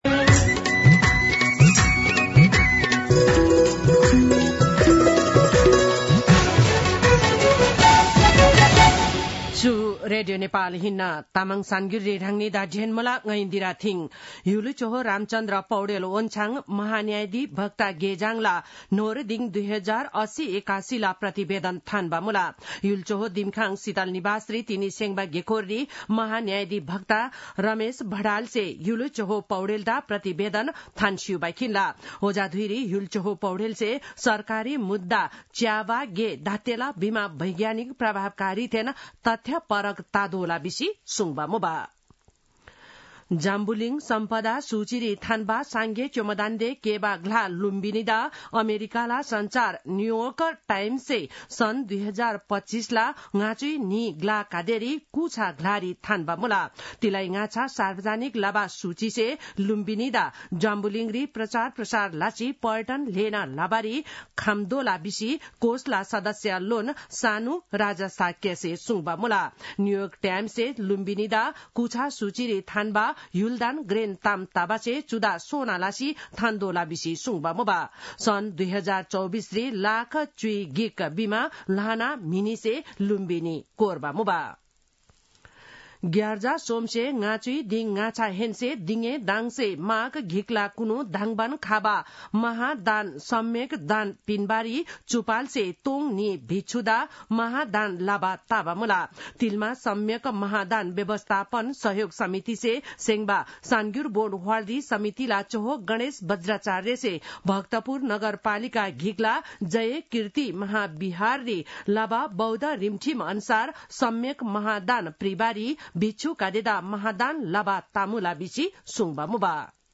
तामाङ भाषाको समाचार : २९ पुष , २०८१